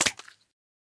Minecraft Version Minecraft Version 25w18a Latest Release | Latest Snapshot 25w18a / assets / minecraft / sounds / mob / pufferfish / flop2.ogg Compare With Compare With Latest Release | Latest Snapshot
flop2.ogg